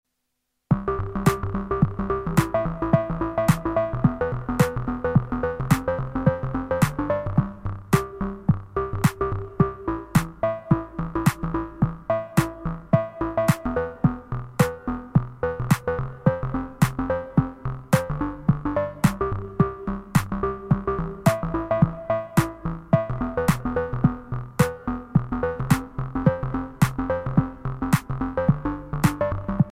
Arpeggiator rhythms. Also cat hairs…